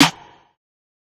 Snare (Skyfall).wav